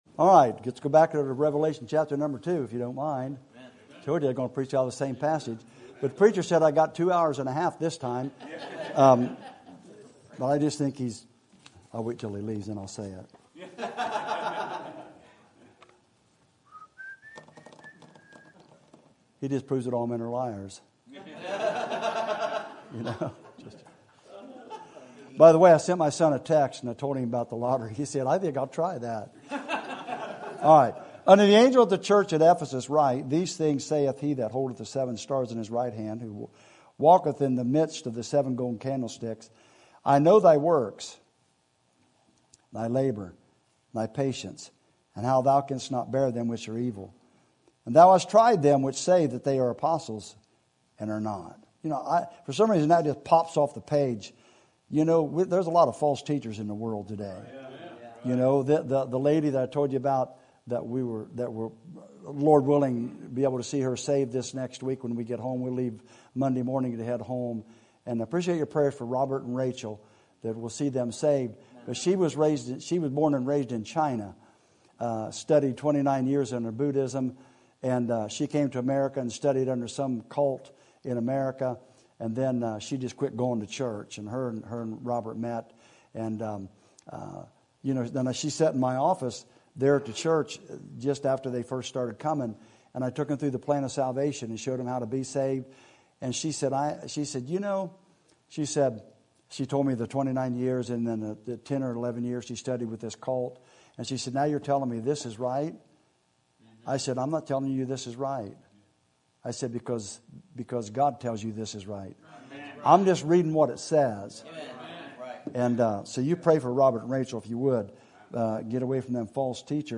Sermon Topic: Men's Meeting Sermon Type: Special Sermon Audio: Sermon download: Download (36.36 MB) Sermon Tags: Revelation Love Repent Men